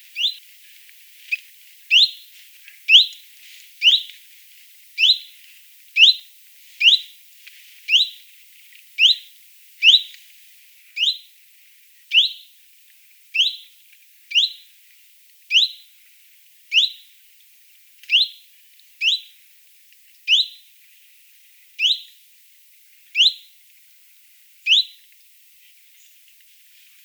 Phylloscopus collybita
E 11°03' - ALTITUDE: 0 m. - VOCALIZATION TYPE: contact call. - SEX/AGE: unknown - COMMENT: Typical call series with a wrong call at 1.3 second (see spectrograms).